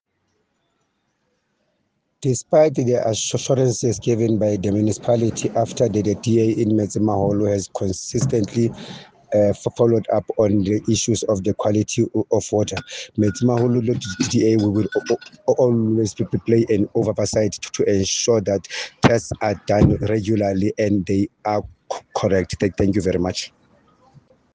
Sesotho soundbites by Cllr Thulani Mbana and